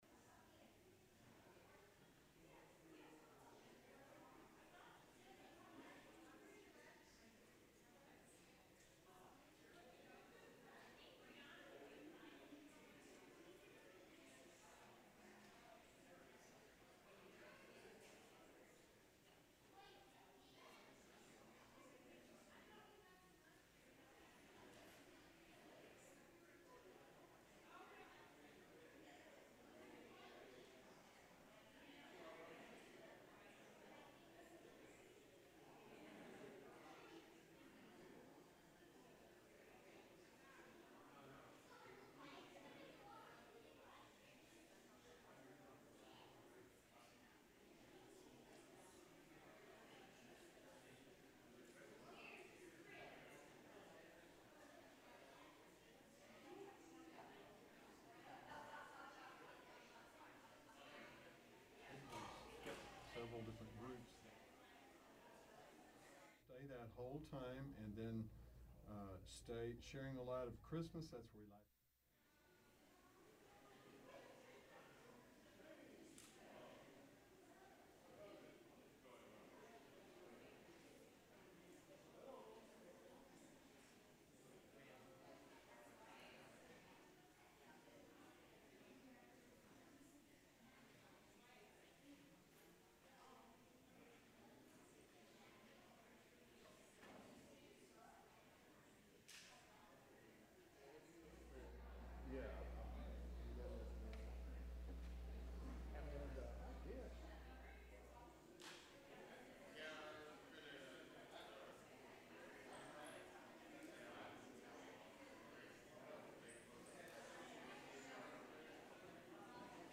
December 24, 2019 Christmas Eve 5pm Service
Traditional Sermon